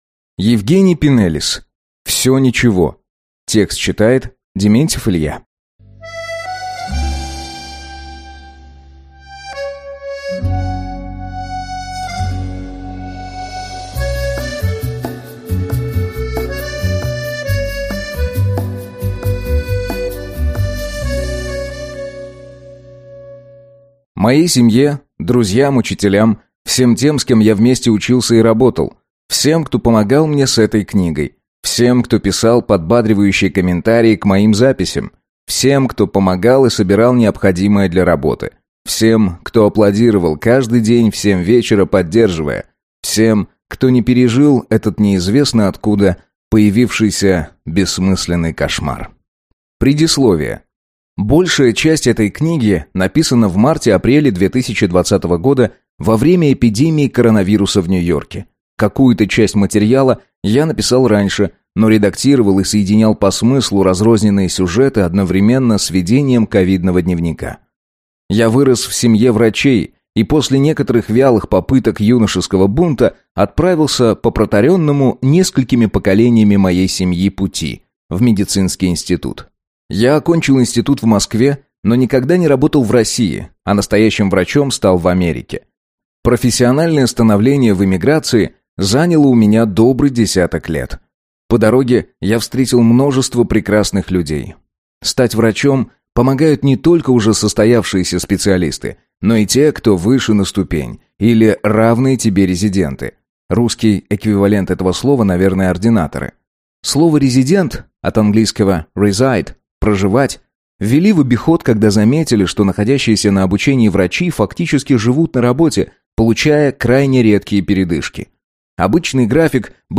Аудиокнига Всё ничего | Библиотека аудиокниг